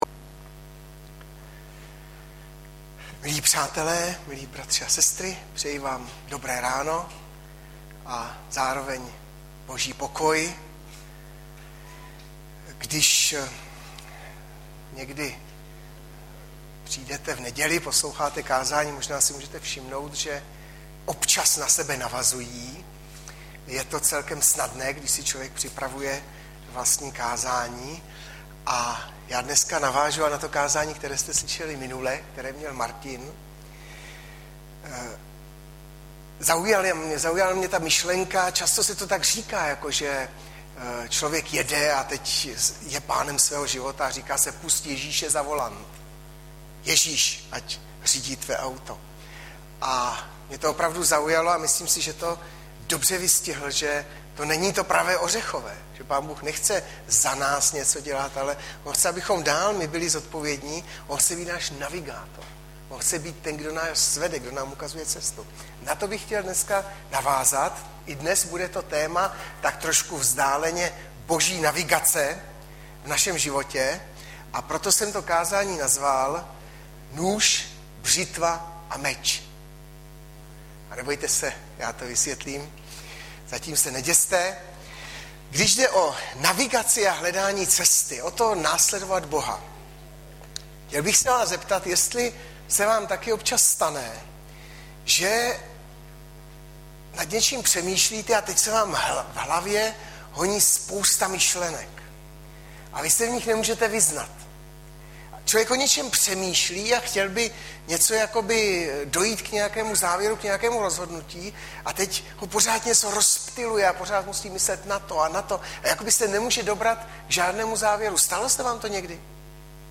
Hlavní nabídka Kázání Chvály Kalendář Knihovna Kontakt Pro přihlášené O nás Partneři Zpravodaj Přihlásit se Zavřít Jméno Heslo Pamatuj si mě  22.07.2012 - NŮŽ, BŘITVA A MEČ - Žd 4,10-12 Audiozáznam kázání si můžete také uložit do PC na tomto odkazu.